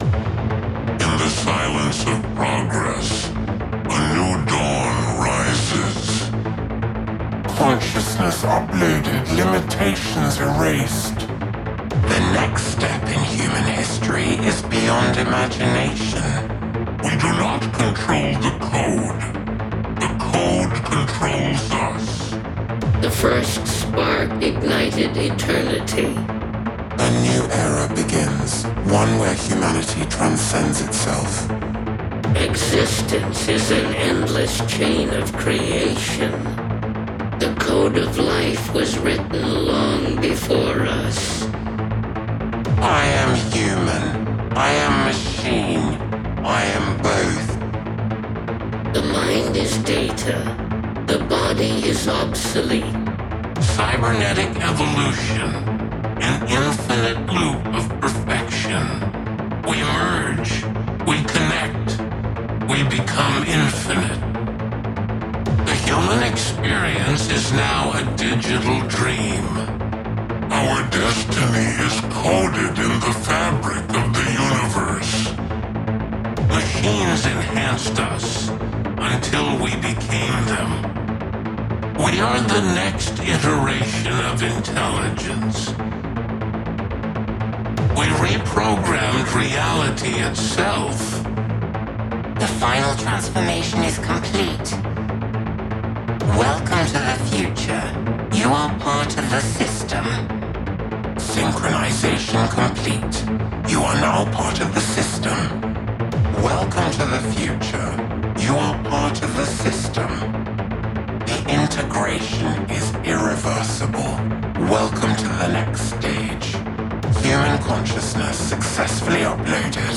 Cinematic / FX Hardcore / Hardstyle Multi-genre Psy-Trance Synthwave / Retrowave Techno Trance Vocals
These 70 powerful voice samples are perfect for Melodic Techno, Trance, Cyberpunk, and Sci-Fi sound design.
70 professionally recorded and processed phrases
Ideal for melodic and atmospheric tracks